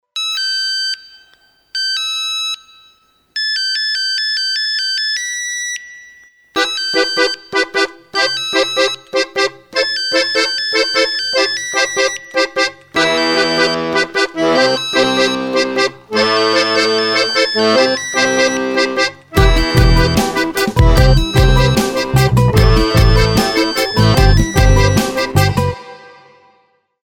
звонкие